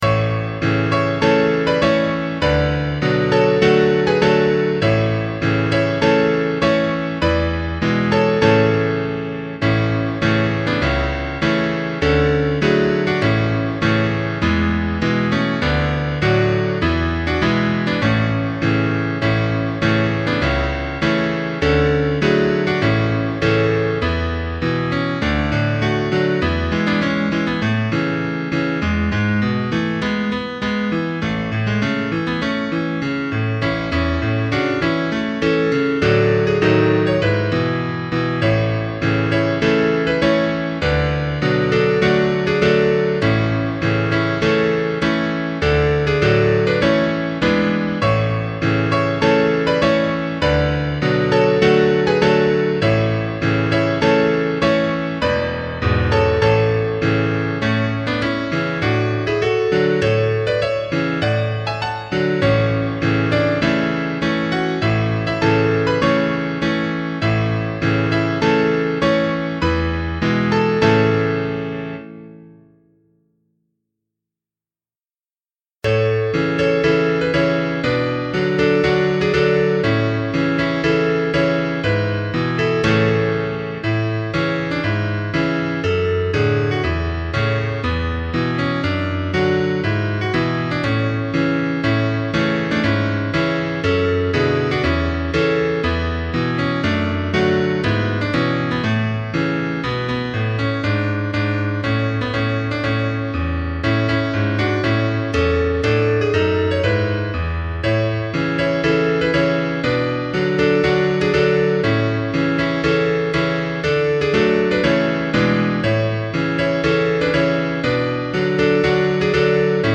大同校歌鋼琴伴奏（一中一快）.mp3